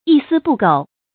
一丝不苟 yī sī bù gǒu
一丝不苟发音
成语正音 苟，不能读作“jù”。